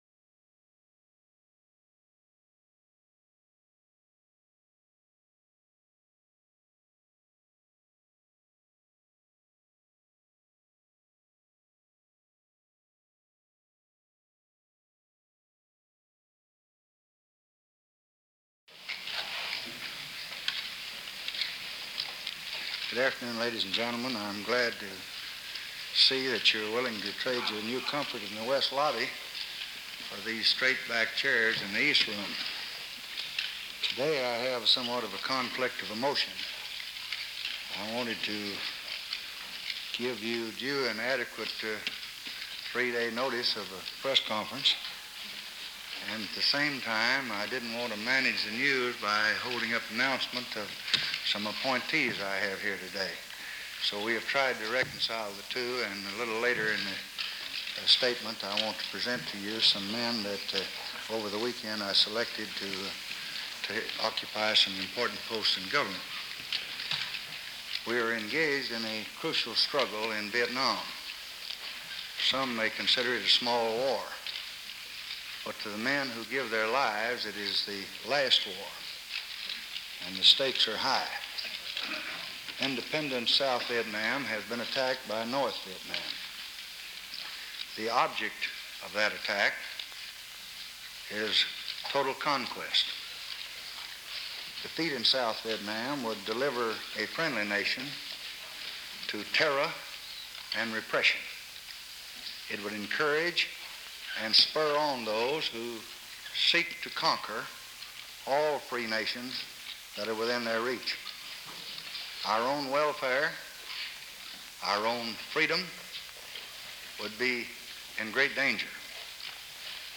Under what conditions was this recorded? April 27, 1965: Press Conference in the East Room